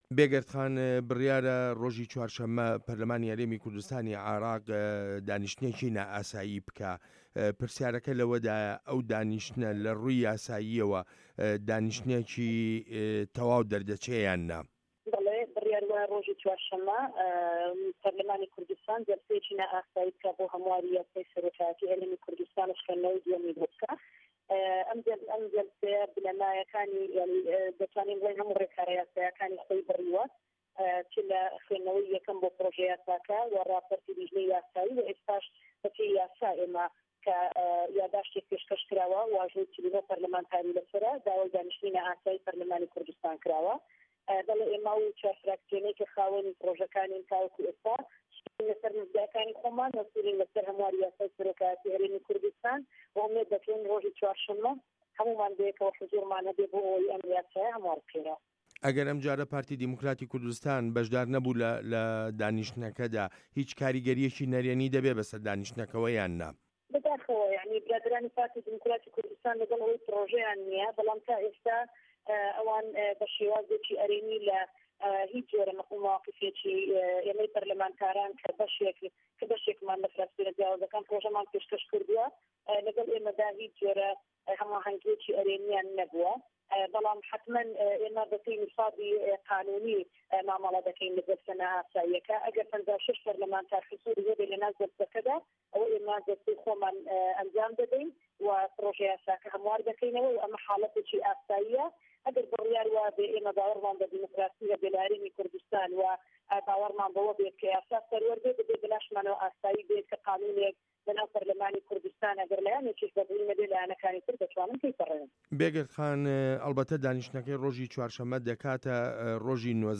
وتووێژ لەگەڵ بێگەر تاڵەبانی